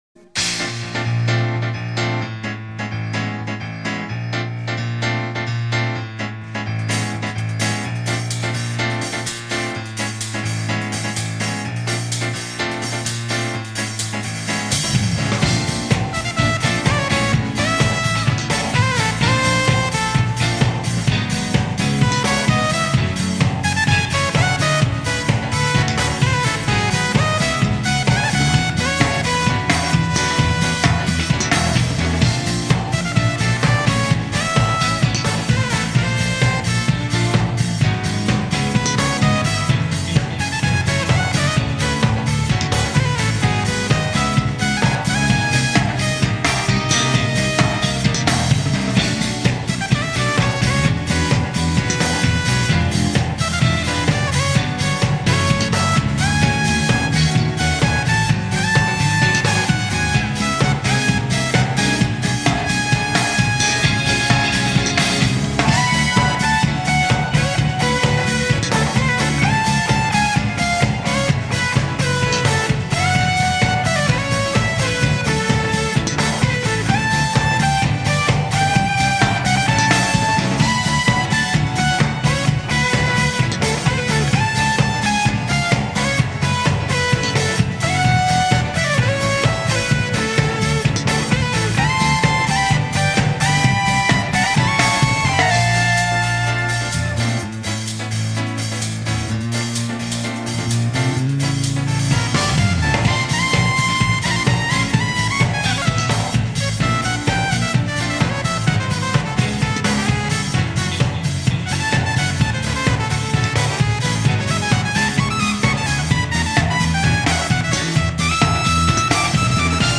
(Live)
음질은 열악하지만 감상엔 지장 없습니다^^